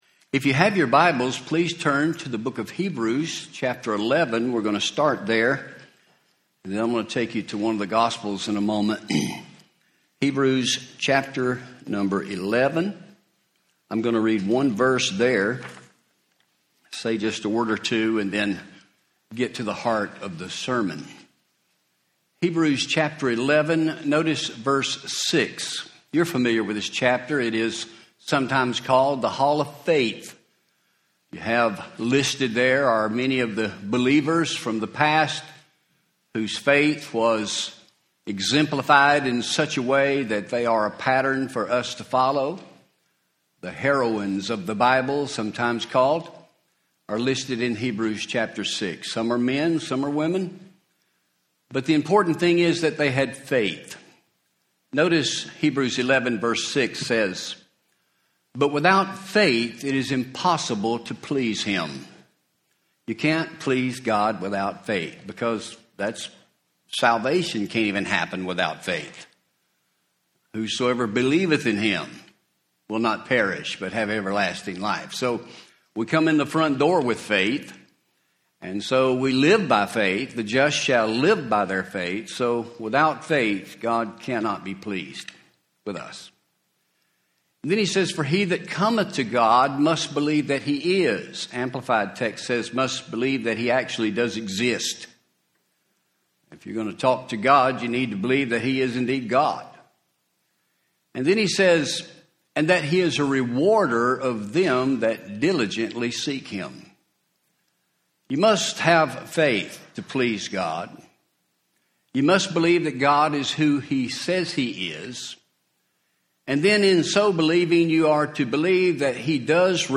A Mother’s Day Sermon Matthew 15:21-28